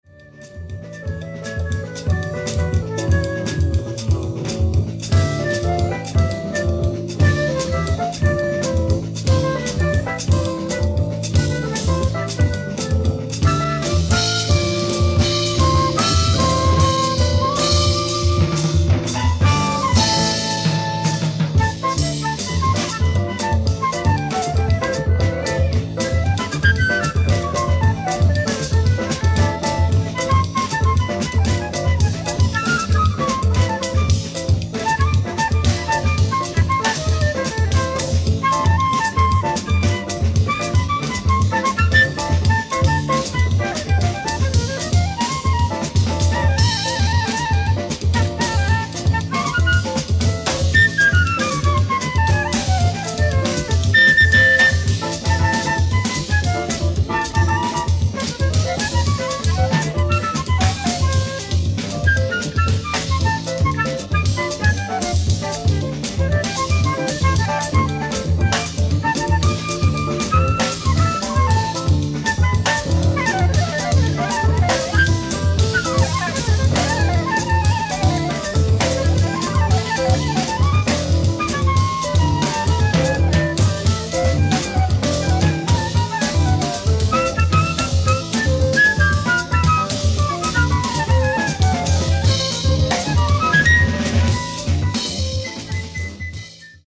ライブ・アット・カフェ・モンマルトル、コペンハーゲン、デンマーク 1972
未発表サウンドボード音源盤！！
※試聴用に実際より音質を落としています。